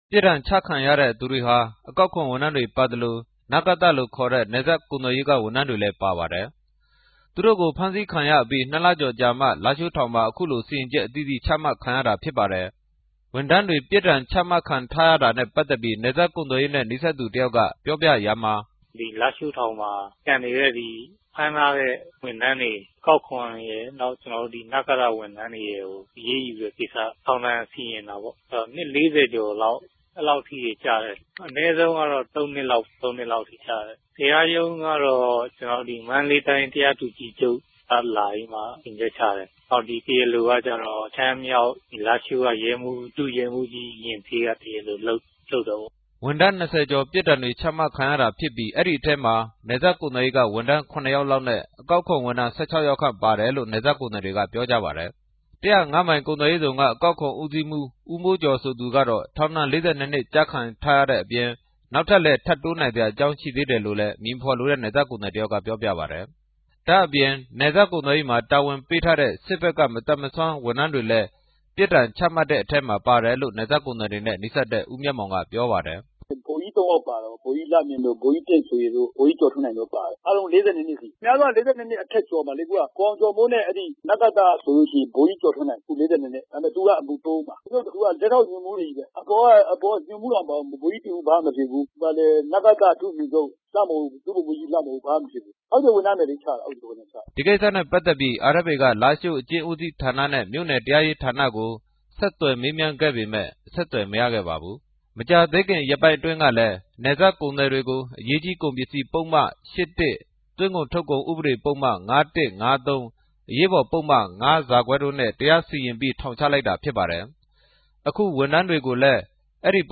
RFA သတင်းထောက်